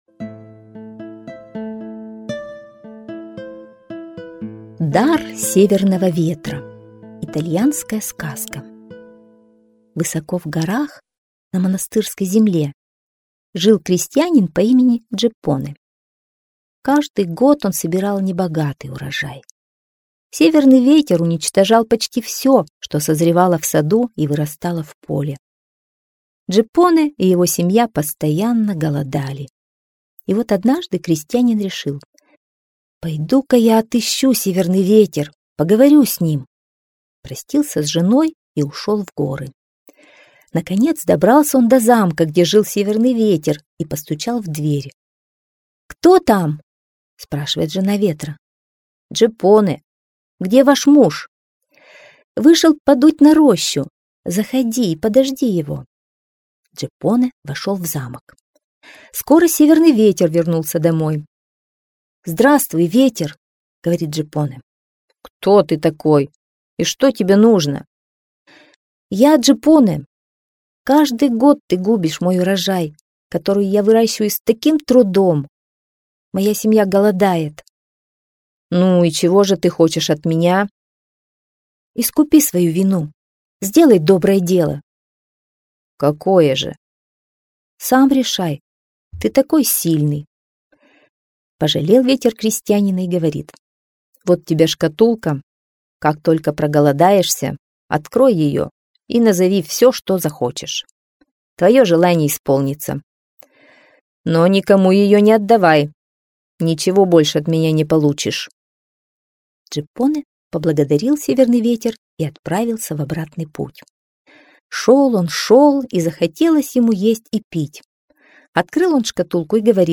Дар Северного Ветра - итальянская аудиосказка - слушать онлайн